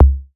REDD PERC (3).wav